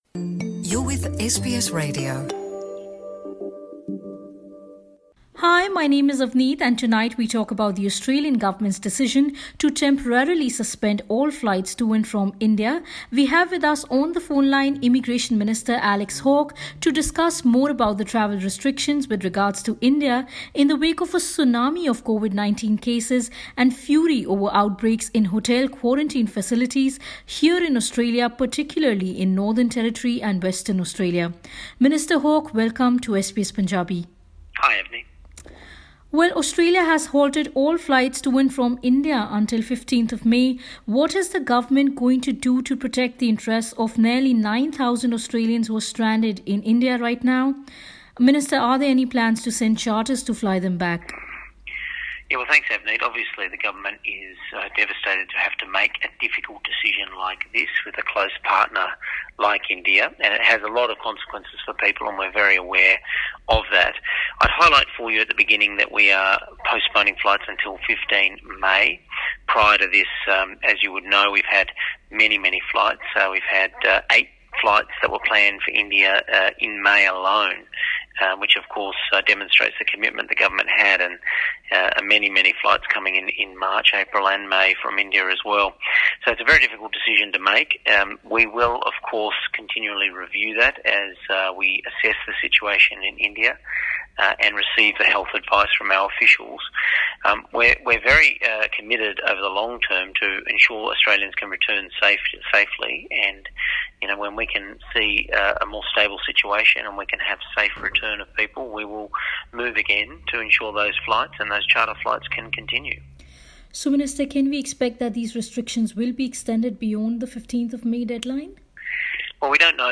ਐਸ ਬੀ ਐਸ ਪੰਜਾਬੀ ਨਾਲ ਇੱਕ ਇੰਟਰਵਿਊ ਵਿੱਚ ਇਮੀਗ੍ਰੇਸ਼ਨ ਮੰਤਰੀ ਅਲੈਕਸ ਹਾਕ ਨੇ ਅੰਤਰਰਾਸ਼ਟਰੀ ਵਿਦਿਆਰਥੀਆਂ ਸਮੇਤ ਆਸਟ੍ਰੇਲੀਆ ਦੇ ਅਸਥਾਈ ਵੀਜ਼ਾ ਧਾਰਕਾਂ ਦੇ ਸਾਹਮਣੇ ਦਰਪੇਸ਼ ਚੁਣੌਤੀਆਂ ਨੂੰ ਸੰਬੋਧਨ ਕੀਤਾ ਜੋ ਕਿ ਕੋਵਿਡ ਤੋਂ ਪ੍ਰਭਾਵਿਤ ਭਾਰਤ ਵਿੱਚ ਬੇਹੱਦ ‘ਮੁਸ਼ਕਲ, ਖਤਰਨਾਕ ਅਤੇ ਪ੍ਰੇਸ਼ਾਨ ਕਰਨ ਵਾਲੇ ਹਾਲਾਤਾਂ ਵਿੱਚ ਫ਼ਸੇ ਹੋਏ ਹਨ।